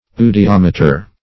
Eudiometer \Eu`di*om"e*ter\, n. [Gr.